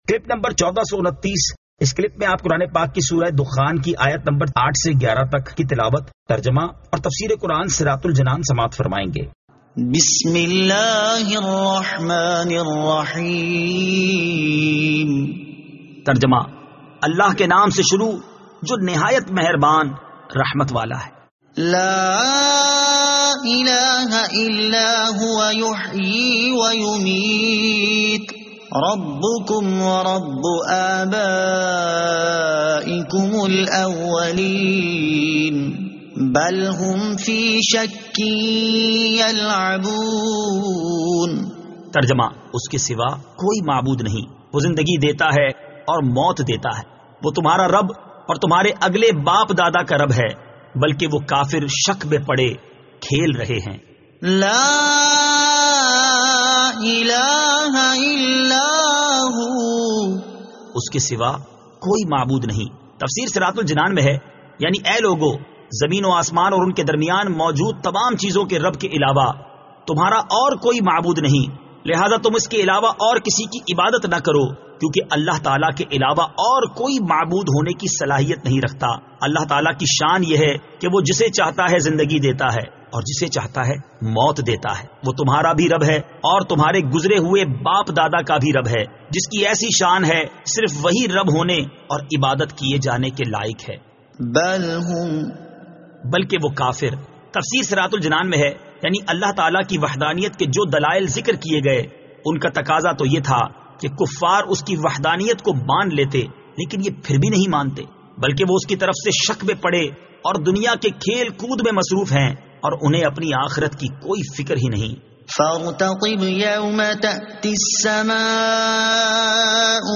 Surah Ad-Dukhan 08 To 11 Tilawat , Tarjama , Tafseer